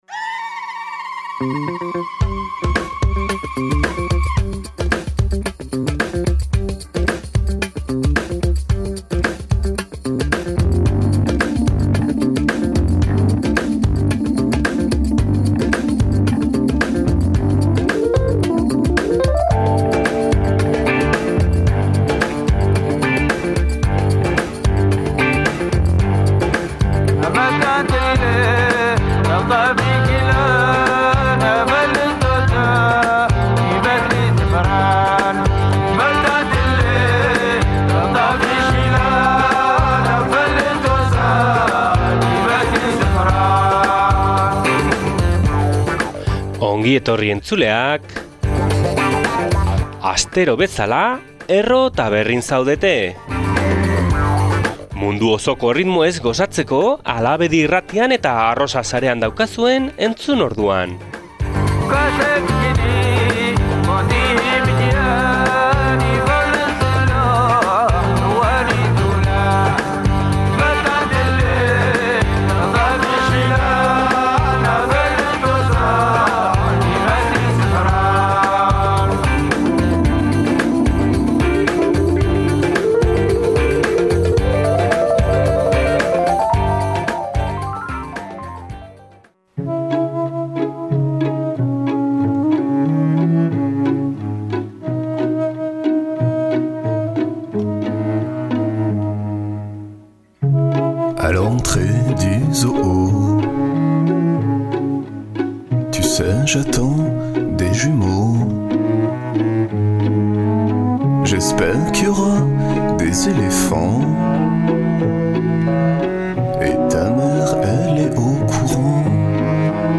Ondoren pianoarekin egingo dugu aurrera melodia eder batzuez lagundurik. Argentina, Violeta Parraren ondare musikala eta Gasteizko Big Band Festibala izango dira ondorengo protagonistak.